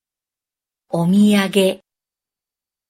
Prononciation de omiyage Origine du mot omiyage et autre sens お土産 est en général répertorié dans les dictionnaires japonais sous l’entrée 土産 ( miyage ) puisque le préfixe honorifique o- est facultatif.
Prononciation-de-omiyage.mp3